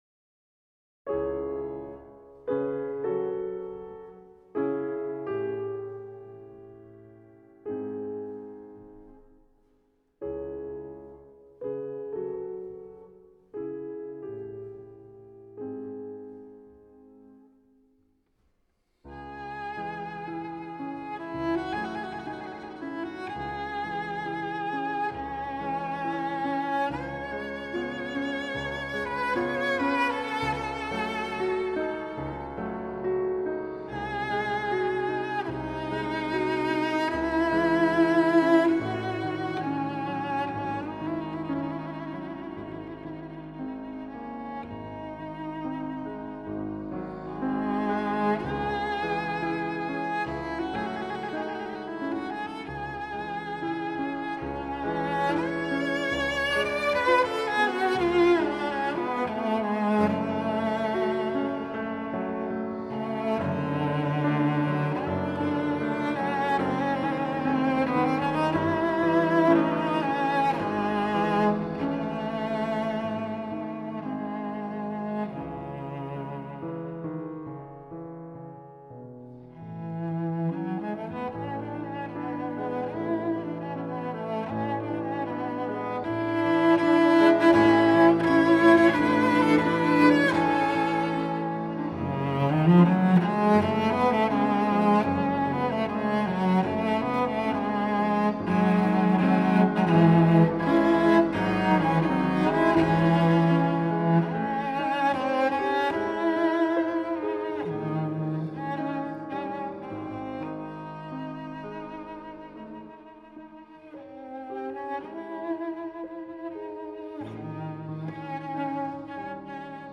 Chill With Chopin (Piano Solo) | Faber Music
Relax with 9 of Chopin's works arranged for piano solo and an accompanying CD of beautiful piano music.
Chill with Chopin allows you to relax with two collections of beautiful tranquil music.